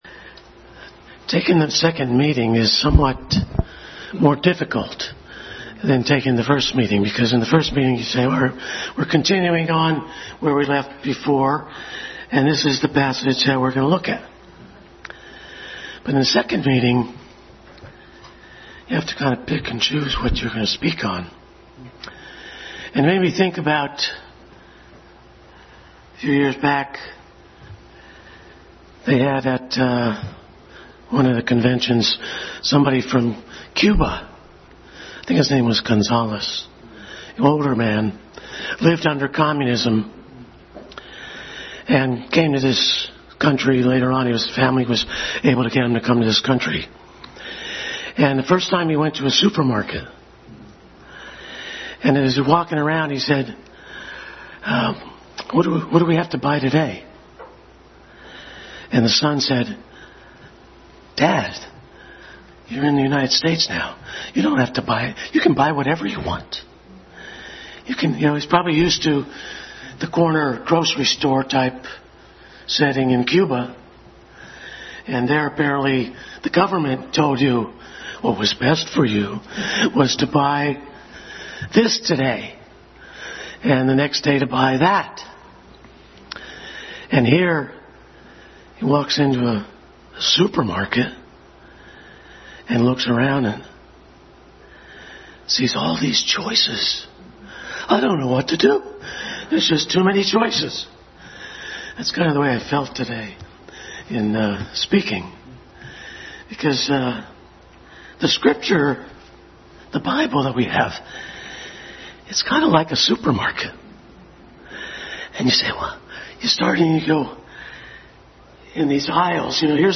Passage: Various Scriptures Service Type: Family Bible Hour Family Bible Hour Message